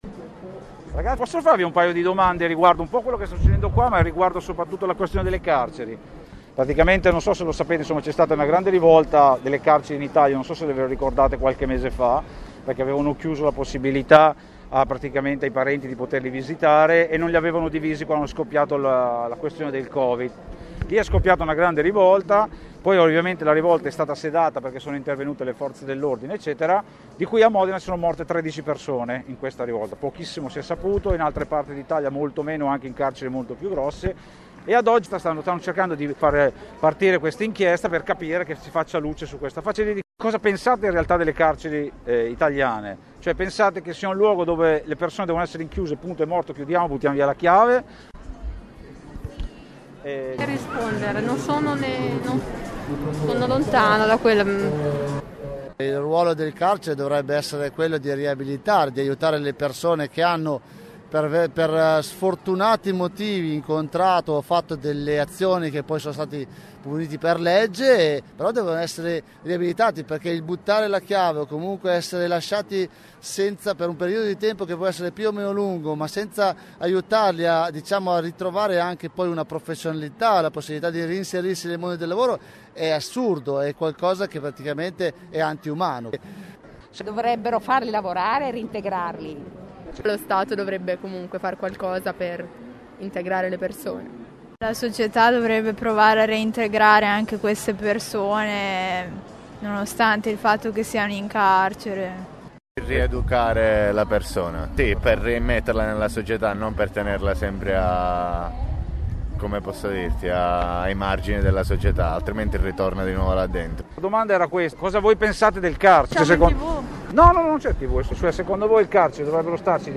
Duranti i numerosi interventi che si sono susseguite con anche collegamenti esterni, (vedi “Senza Quartiere” ), noi abbiamo provato a chiedere ai cittadini presenti, il loro parere rispetto a questo argomento.